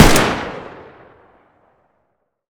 rpk47_fire1.wav